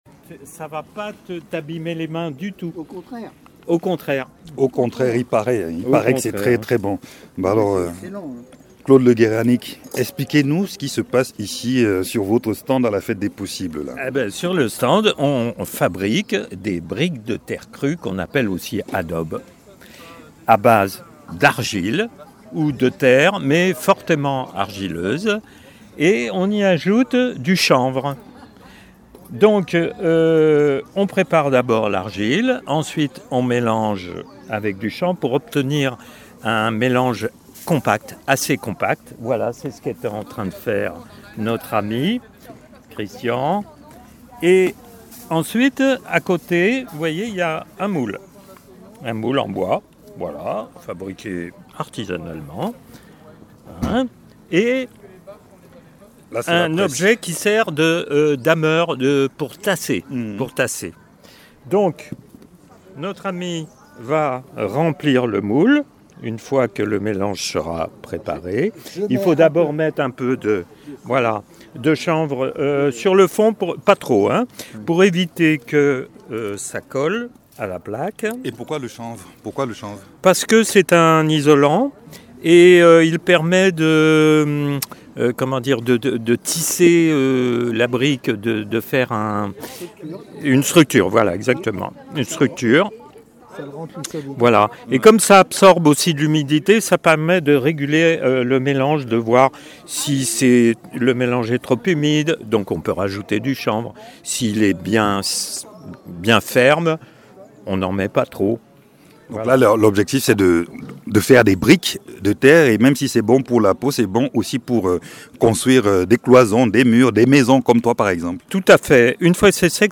Deux reportages, deux regards complémentaires pour explorer d’autres manières d’habiter le monde.